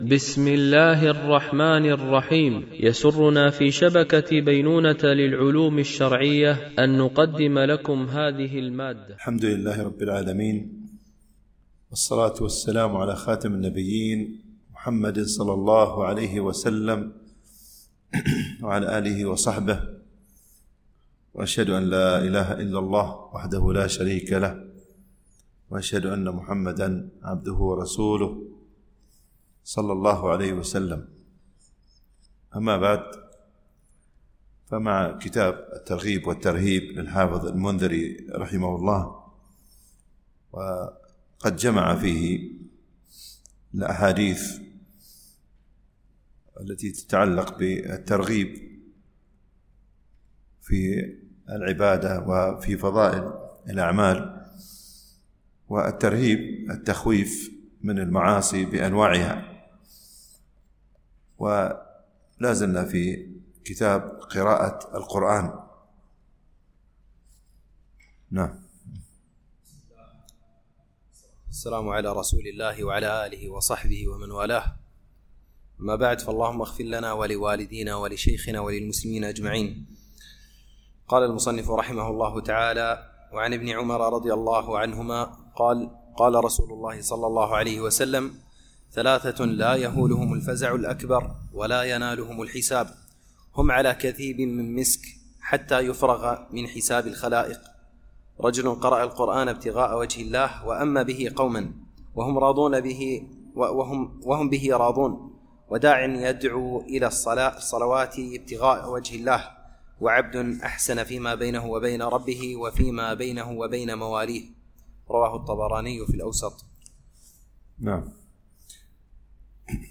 شرح كتاب الترغيب والترهيب - الدرس 194 ( كتاب قراءة القرآن )